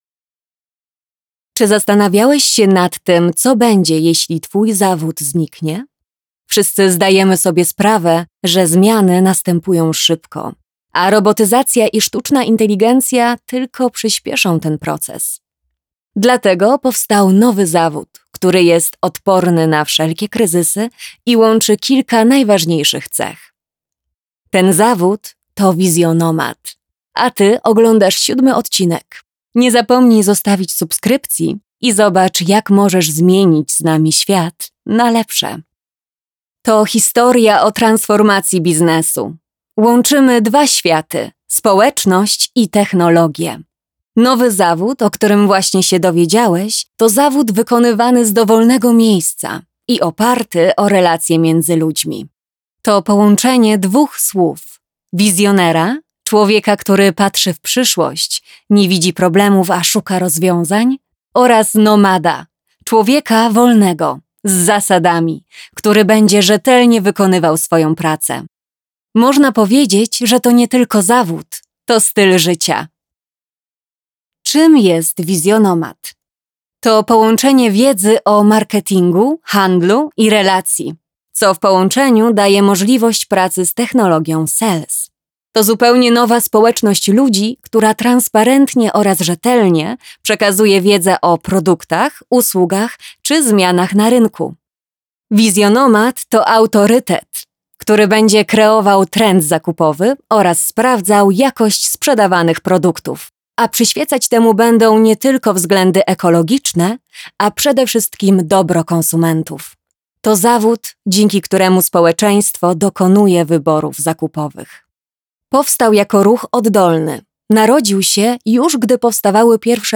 Commerciale, Naturelle, Amicale, Chaude, Douce
Vidéo explicative
Thanks to her acting experience, she has a wide vocal range and excellent diction.